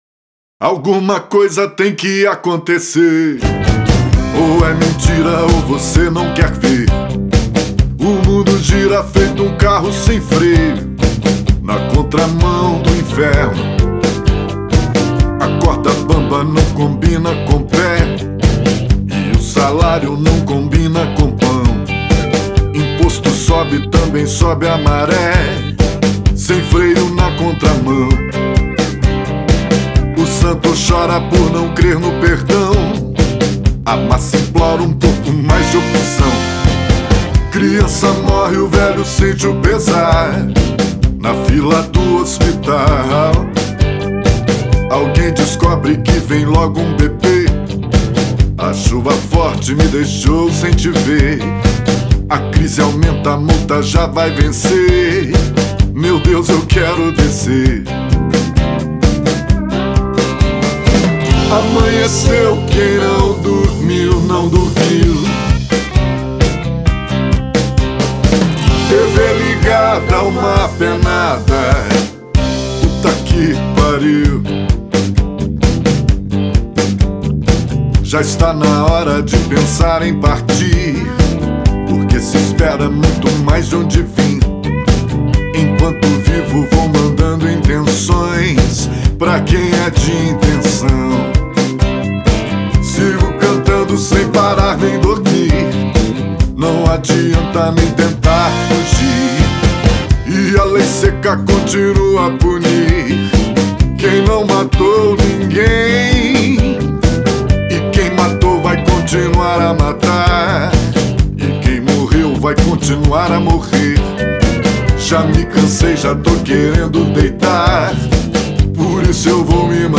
EstiloCountry
Cidade/EstadoBelo Horizonte / MG